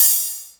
SYN_HH_OP.wav